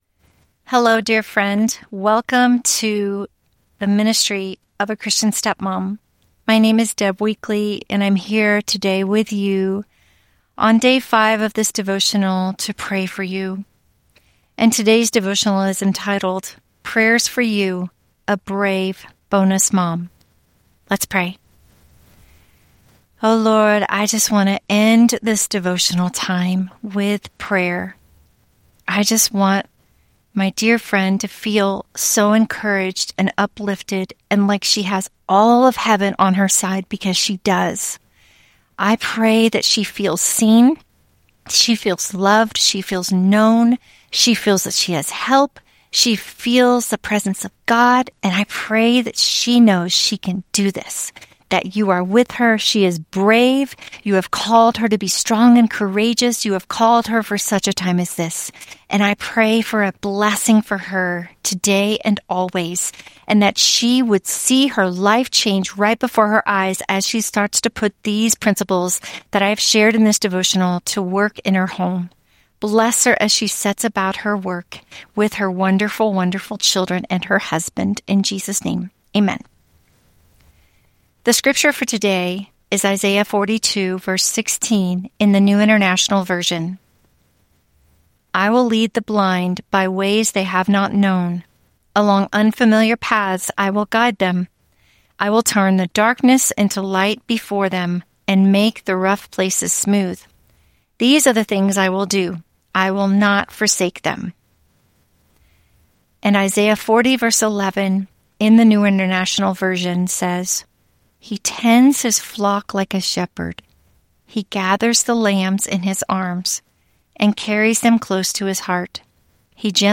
I will pray for you, and then I will read prayers that I pray for my own grandchildren over your children, and bonus kids.